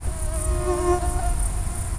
soft and annoying
(mosquito)
muecke.au